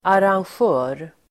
Uttal: [aransj'ö:r]